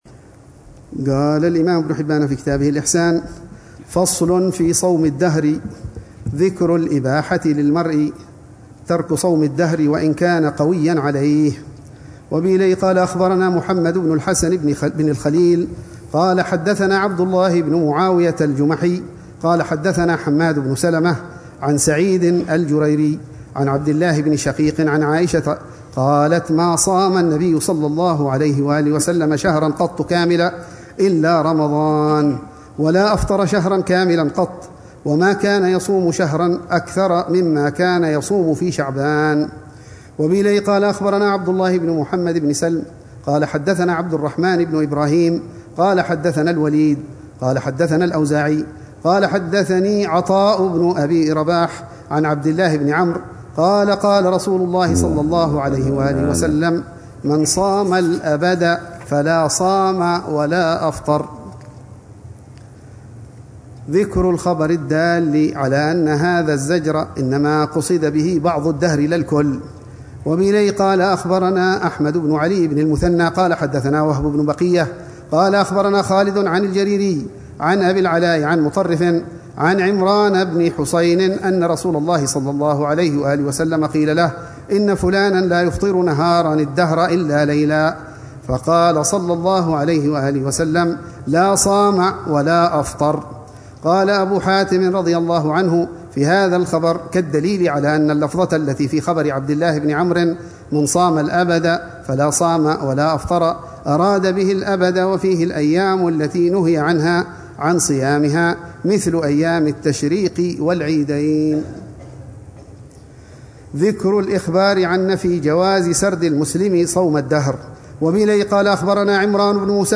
الروحة الرمضانية الخامسة والعشرون بدار المصطفى لعام 1446هـ ، وتتضمن شرح الحبيب العلامة عمر بن محمد بن حفيظ لكتاب الصيام من صحيح ابن حبان، وكتا